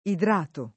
idratare v.; idrato [ idr # to ]